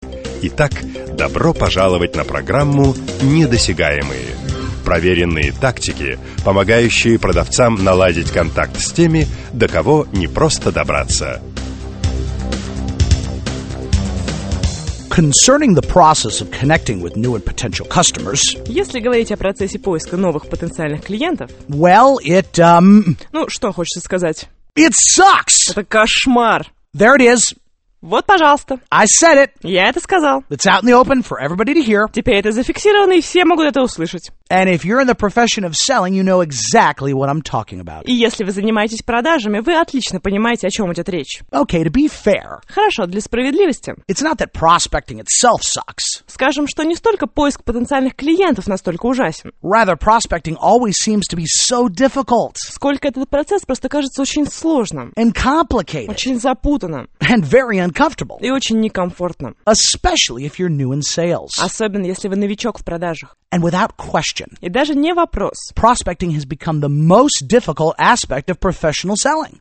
Аудиокнига Недосягаемые | Библиотека аудиокниг